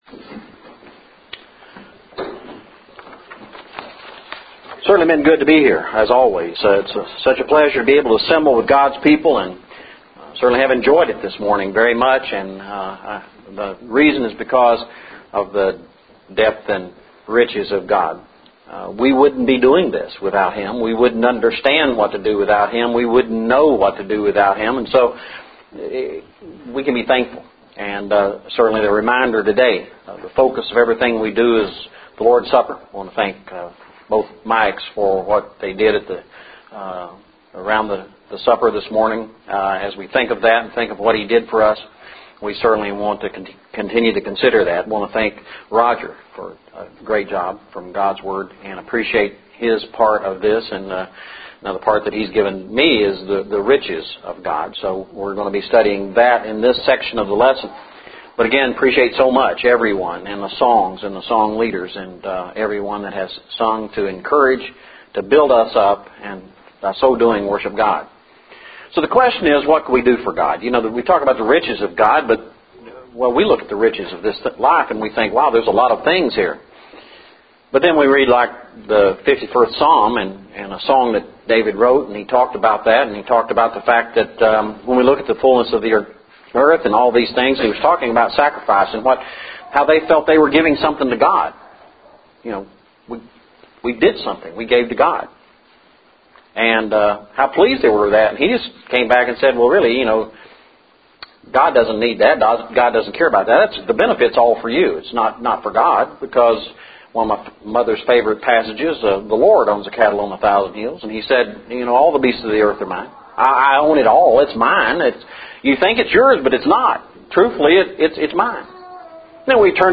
Recorded Lessons